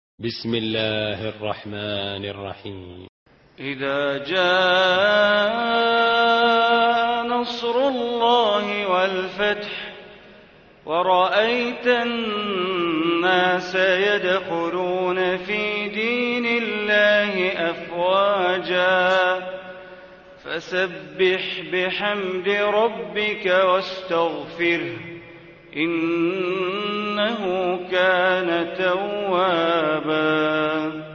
Surah Nasr Recitation by Sheikh Bandar Baleela